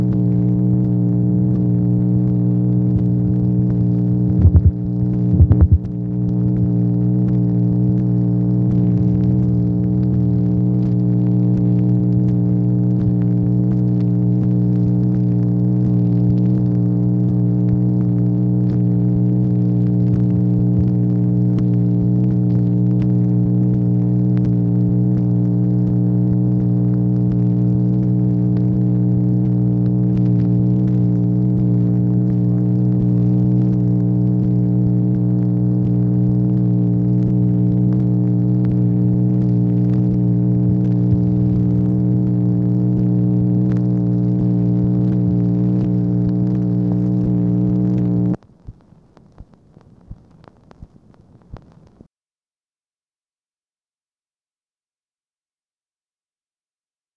MACHINE NOISE, July 23, 1964